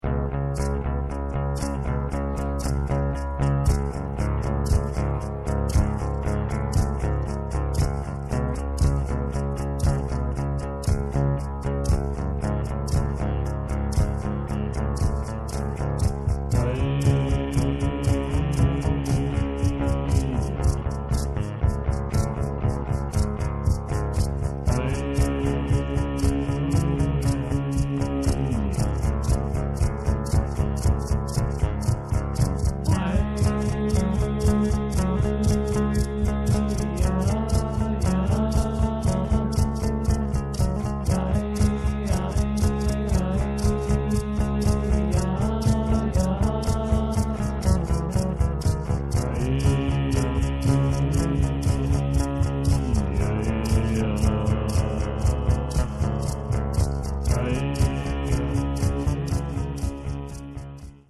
Rickenbacker 4001